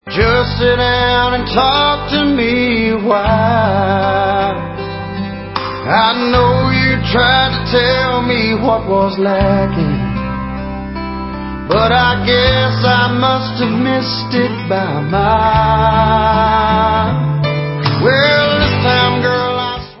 sledovat novinky v kategorii Country